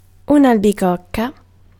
Ääntäminen
IPA: /al.biˈkok.ka/